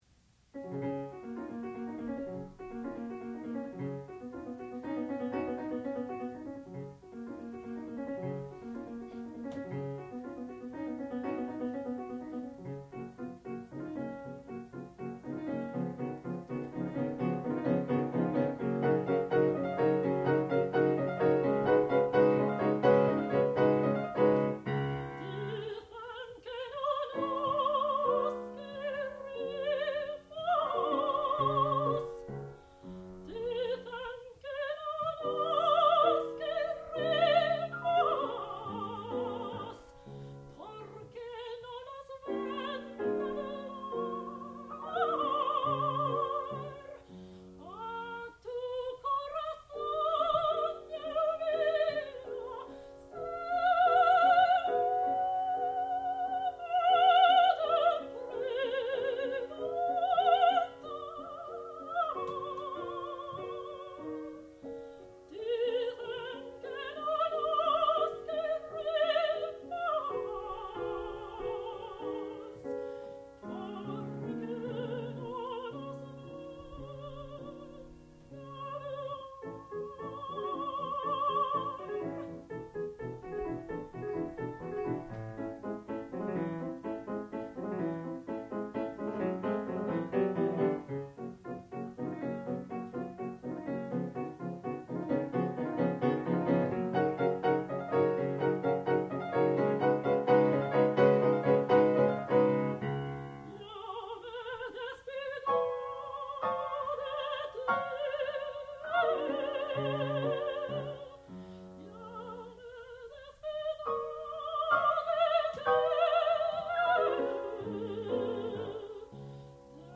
sound recording-musical
classical music
piano
soprano
Master's Recital